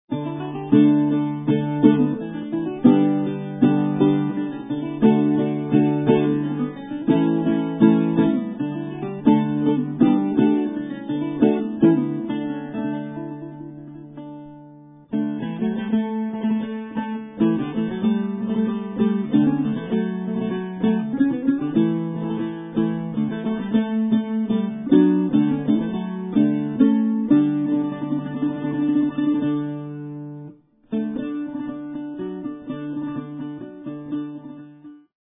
he plays the seldom heard Baroque guitar